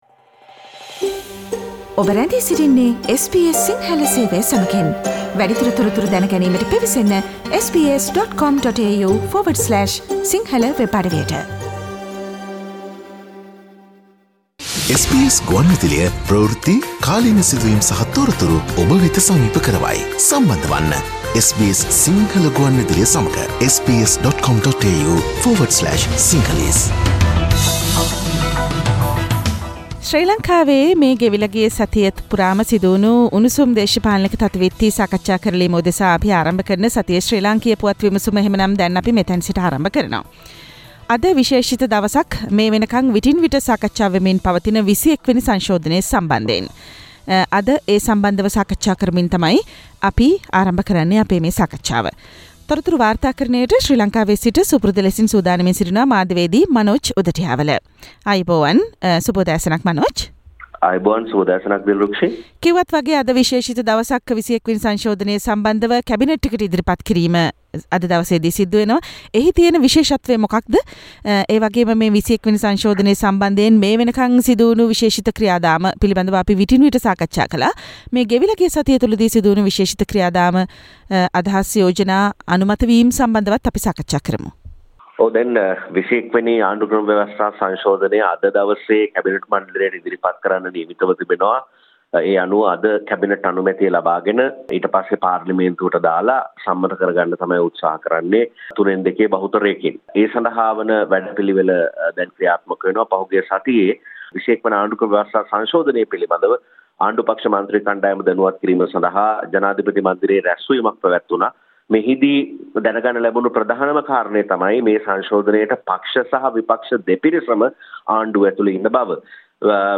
Sri Lanka’s Weekly Political Highlights